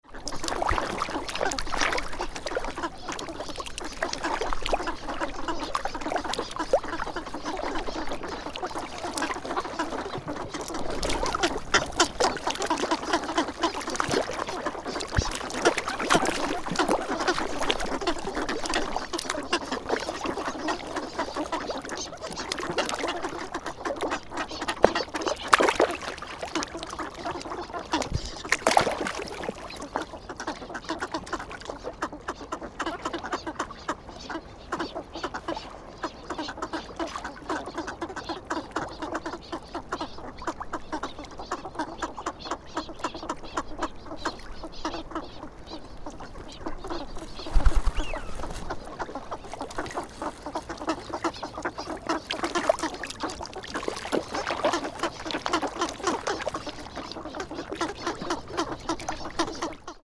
Fukushima Soundscape: Abukuma River
Abukuma River (near Kotori no Mori (forest for birds))
Many ducks came to this river, as usual.
Most of the ducks here were pintails, the same as last year at this time of the year, and there were no swans.